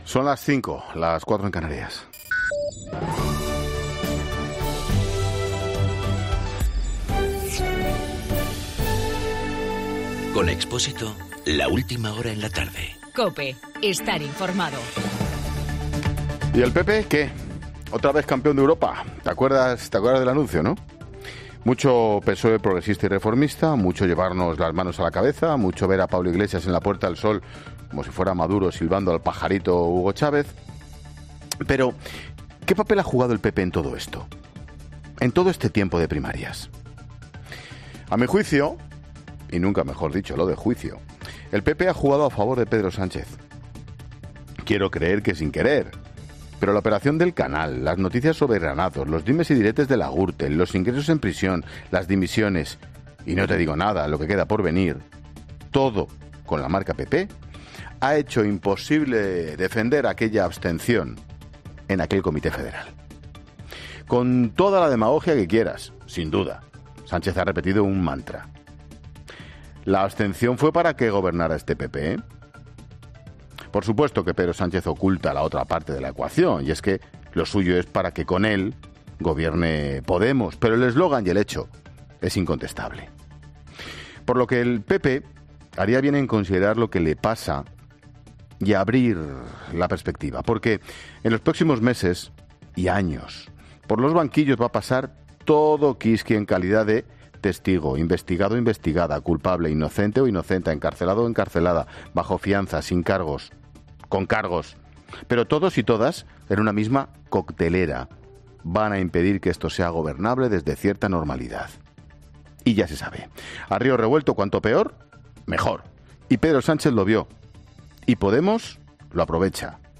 AUDIO: Monólogo 17h